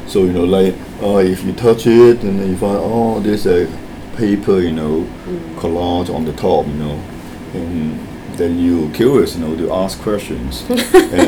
Corpus of Misunderstandings from the Asian Corpus of English
S1 = Taiwanese female S2 = Hong Kong male Context: S2 is talking about different kinds of art, and the experiences of people when they see it.
Intended Words : collage , curious Heard as : come rough , kill it Discussion : The pronunciation of collage is fairly standard, though it may have [dʒ] rather than the expected [ʒ] at the end.
The pronunciation of curious is [kɪrɪs] , though perhaps the biggest problem is that it is spoken quite fast.